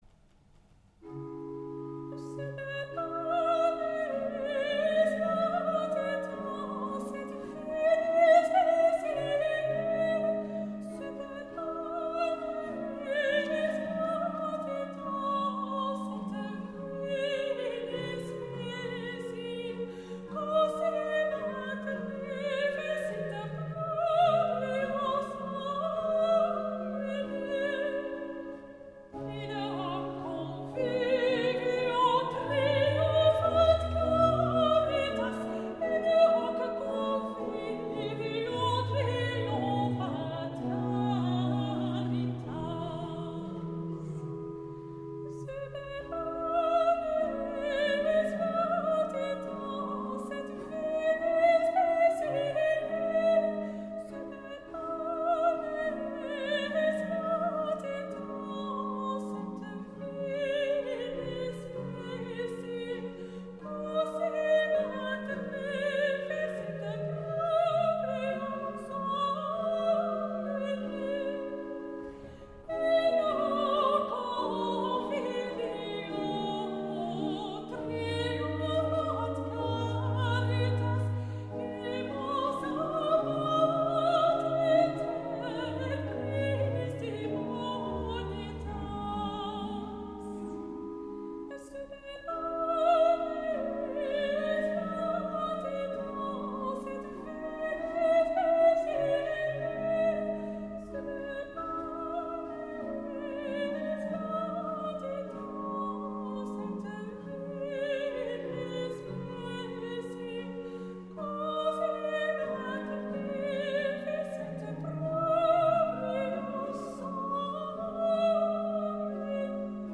Petit motet
~1600 (Baroque)